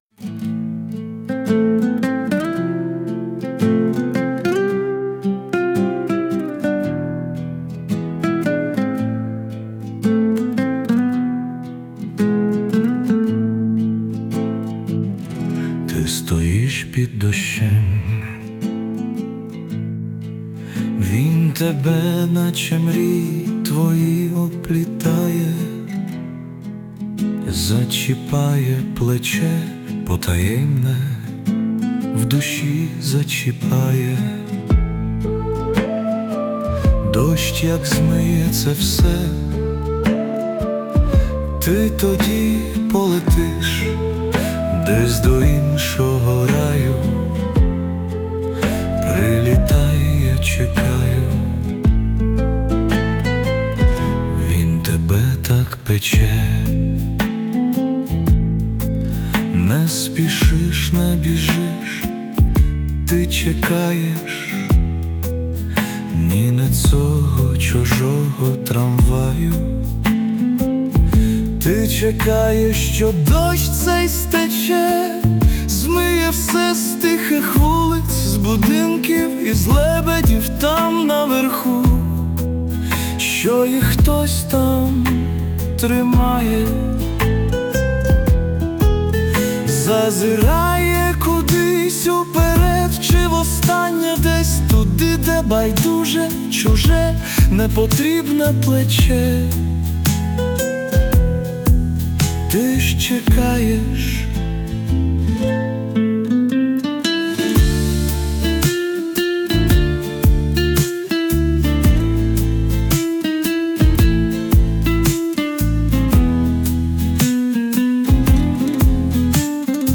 Музичне прочитання з допомогою ШІ
СТИЛЬОВІ ЖАНРИ: Ліричний